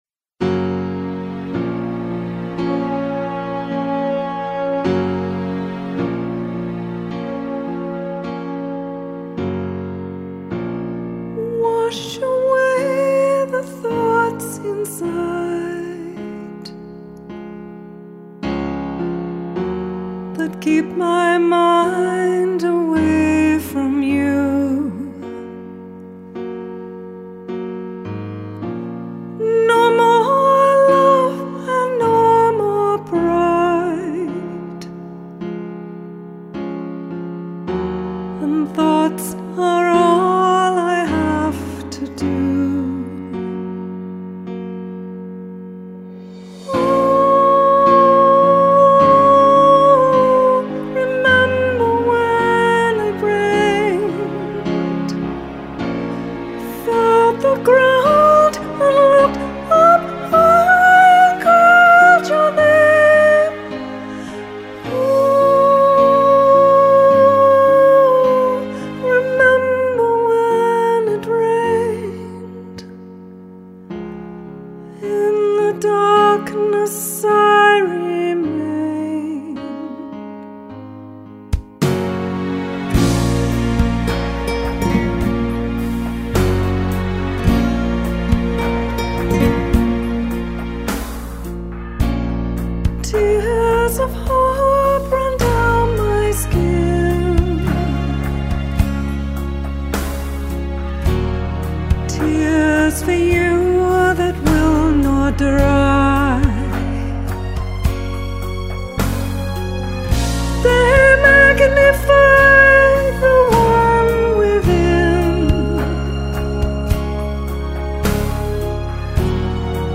Demo tracks recorded & mixed in Vancouver, BC Canada at:
Studio Recorded October, 2017